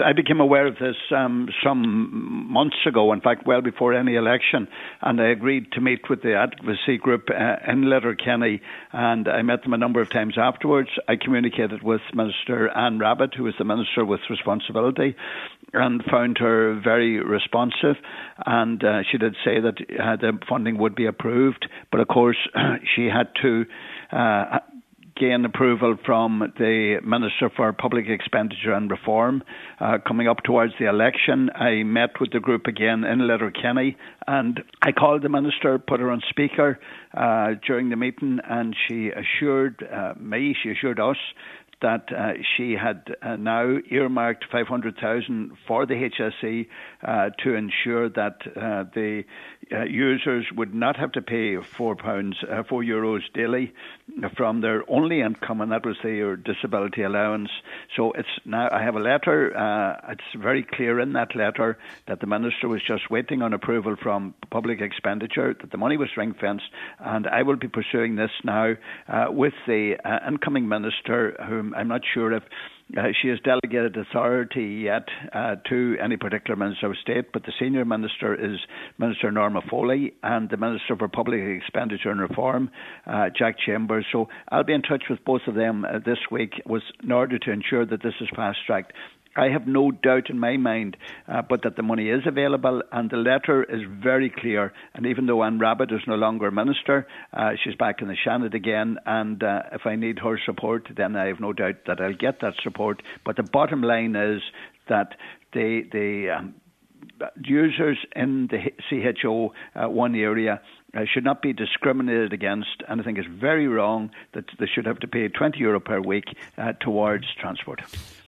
On today’s Nine til Noon Show, he said he’ll be pursuing that this week……..